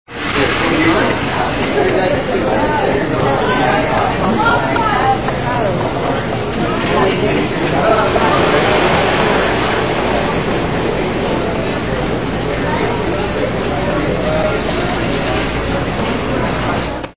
Descarga de Sonidos mp3 Gratis: mercado 1.
market1.mp3